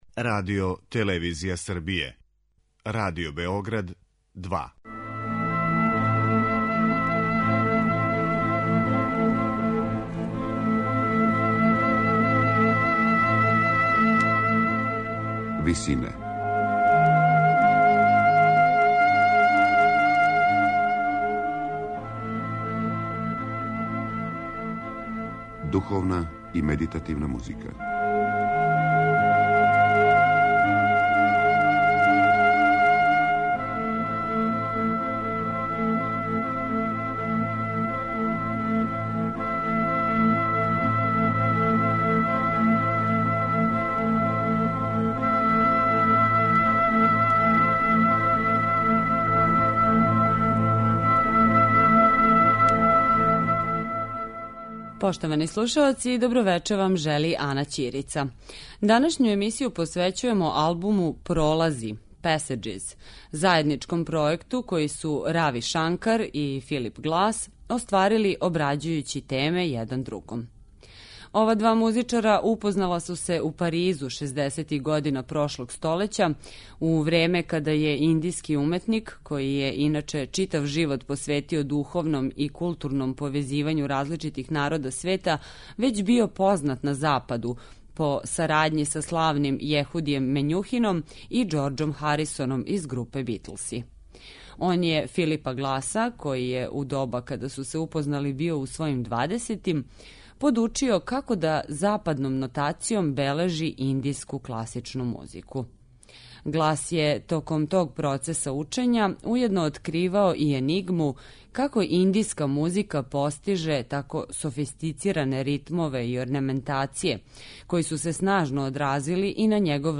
Емисијa медитативне и духовне музике